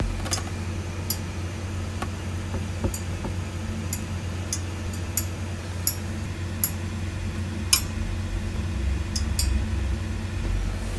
Möglicherweise unnötige Frage aaaaaber ich habe das so noch nicht in der Lautstärke gehört: Schalte ich meinen Marshall DSL20 aus, höre ich für 15 Minuten ein Klirren/Ticken (?).